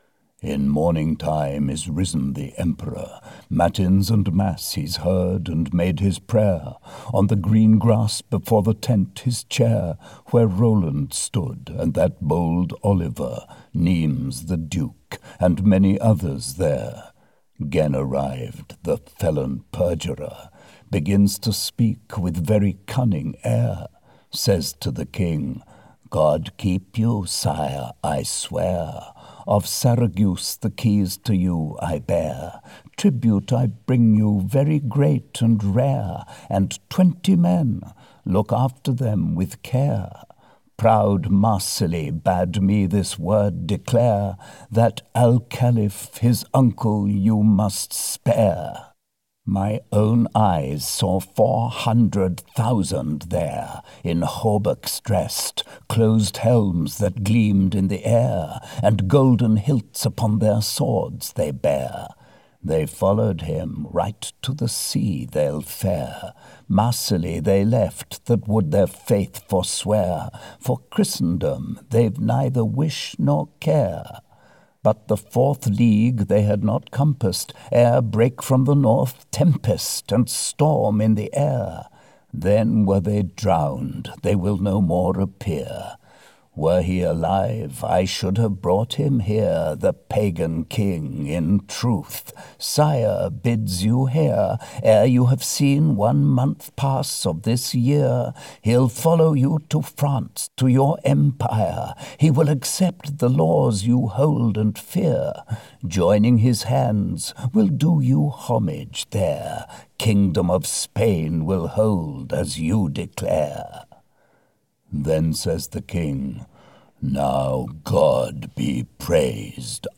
The Song of Roland (EN) audiokniha
Ukázka z knihy